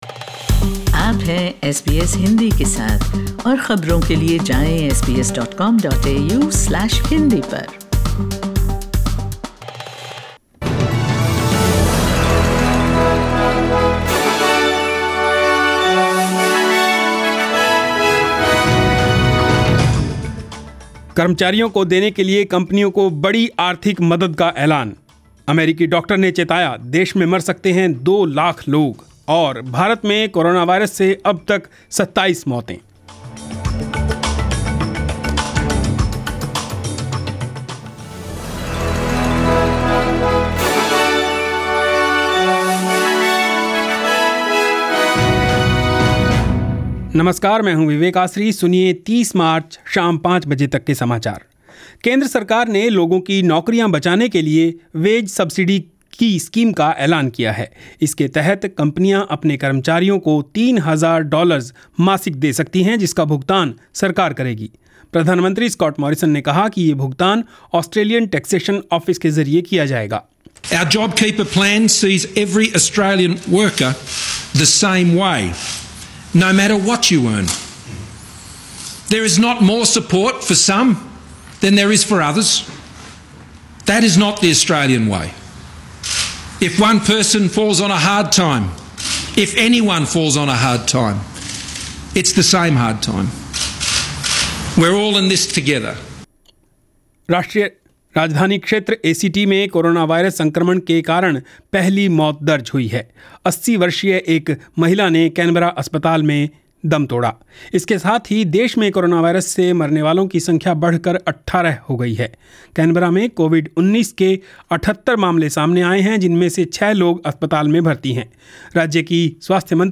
Catch the latest News of 30/03/2020. In this bulletin, ** The federal government announces a wage subsidy scheme in a bid to reduce COVID-19 job losses. ** The ACT records its first coronavirus-related death. and * In India, Coronavirus cases jump over 1000.